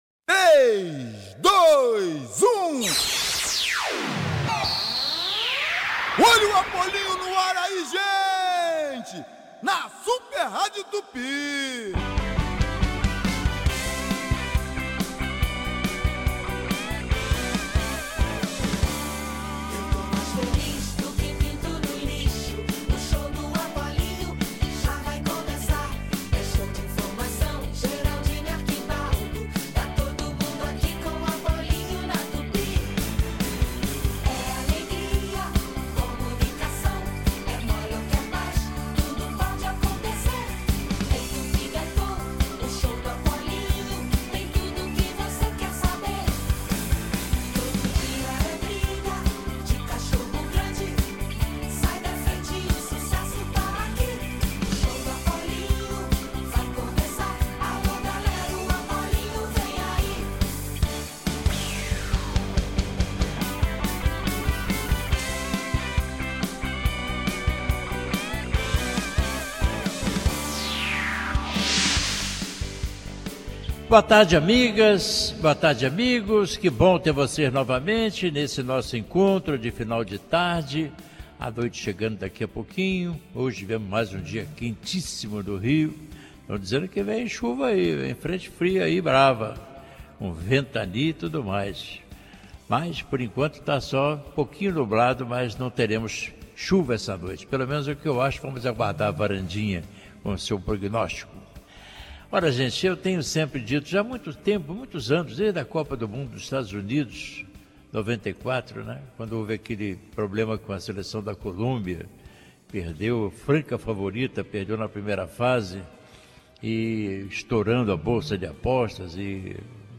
Ouça os comentários de Washington Rodrigues, o Apolinho: